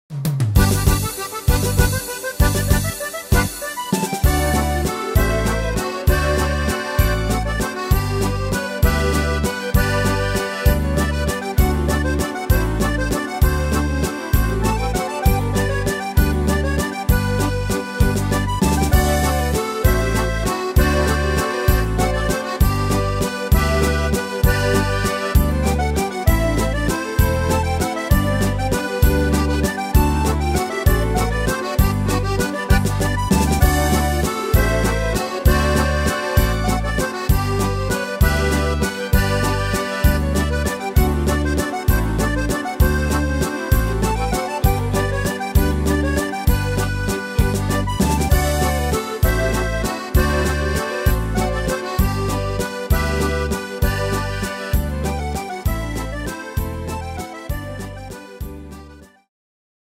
Tempo: 196 / Tonart: G-Dur